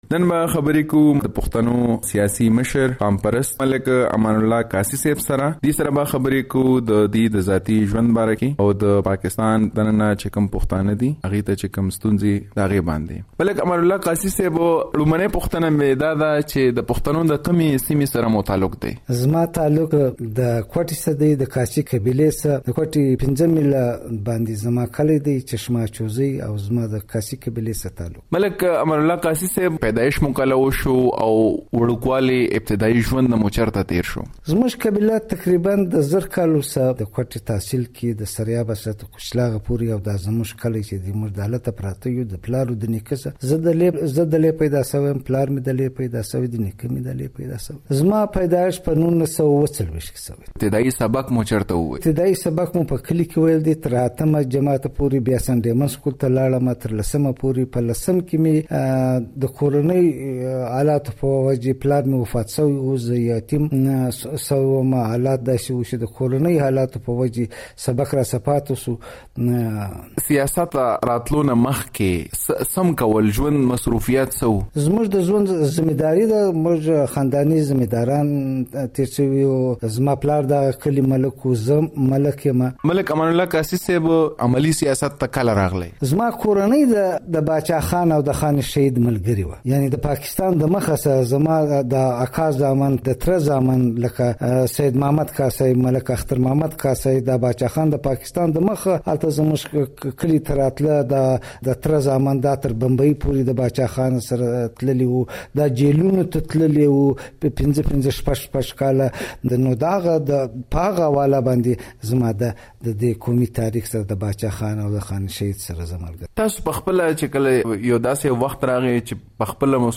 ځانګړې مرکه په اسلام اباد کې